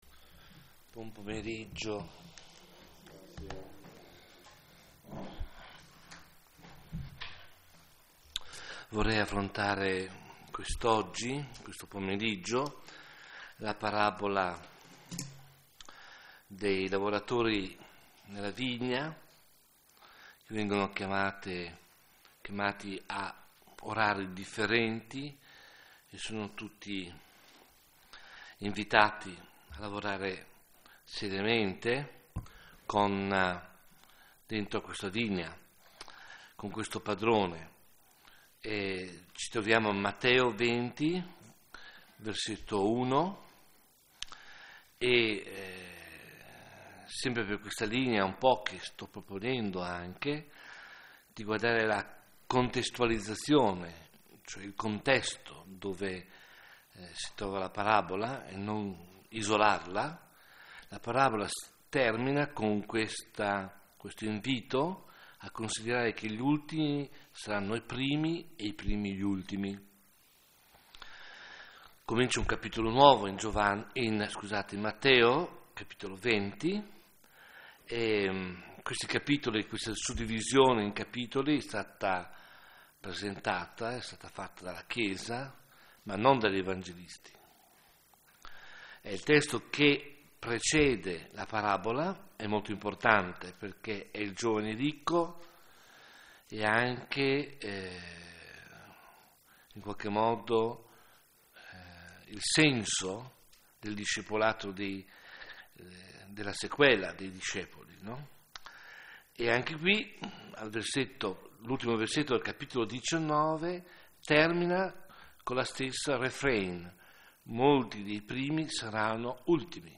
Serie: Meditazione